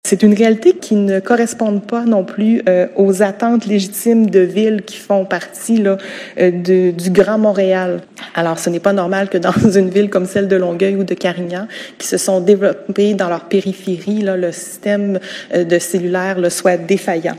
La présidente du conseil d’arrondissement de Saint-Hubert, Nathalie Delisle, a indiqué lors du conseil municipal de mars que ce problème touchait surtout son district des Maraîchers ainsi que le district des Salières à Carignan.